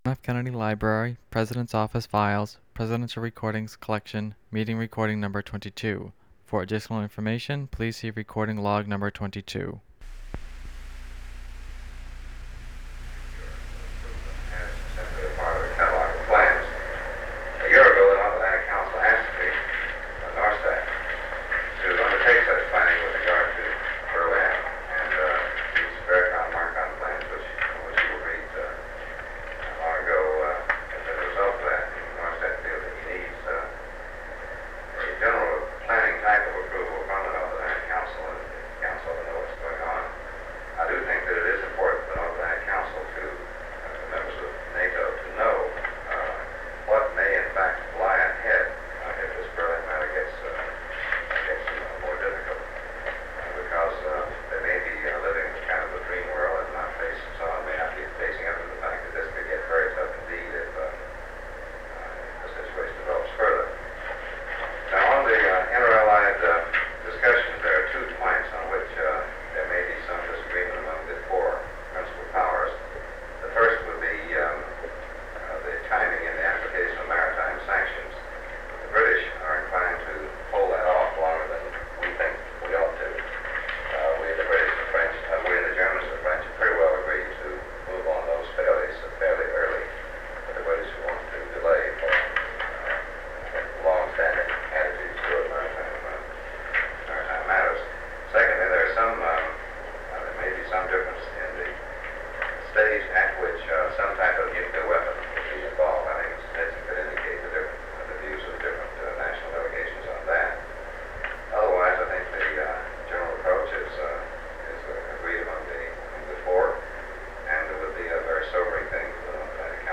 Secret White House Tapes | John F. Kennedy Presidency Meeting on Berlin Rewind 10 seconds Play/Pause Fast-forward 10 seconds 0:00 Download audio Previous Meetings: Tape 121/A57.